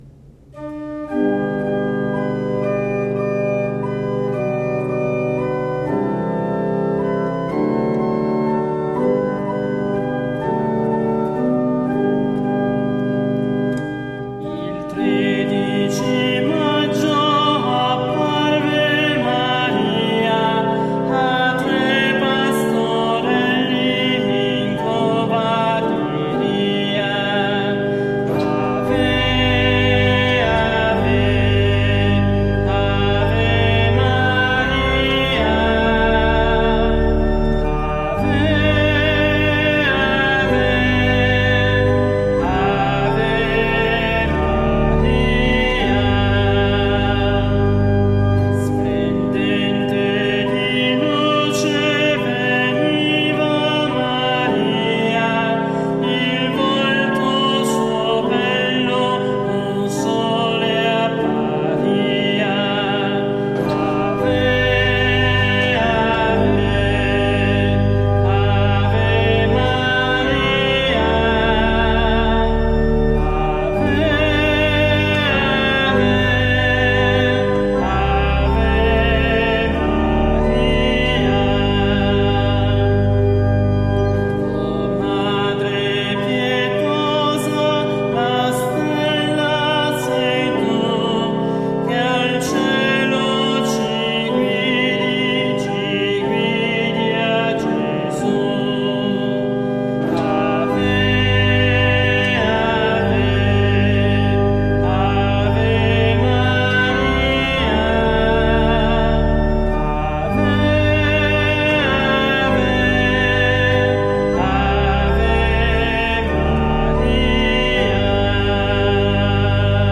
All'organo Agati
organista e solista